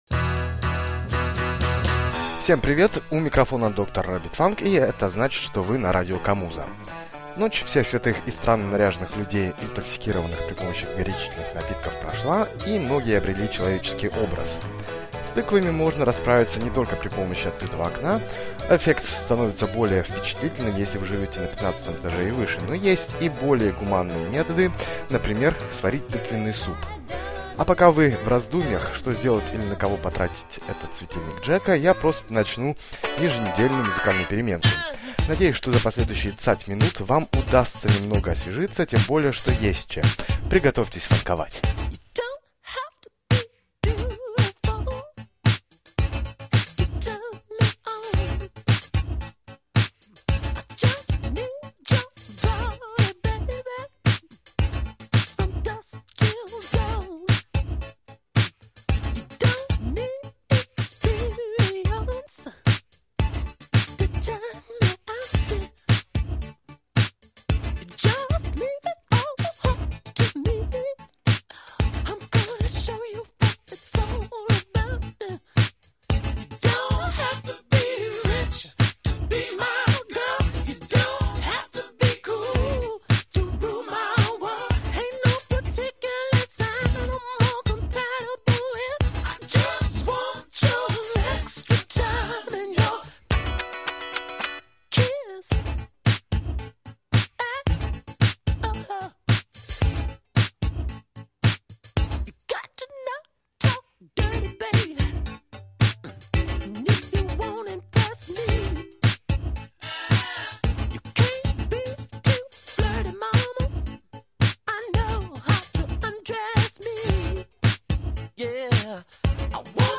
funk
сборник фанк-музыки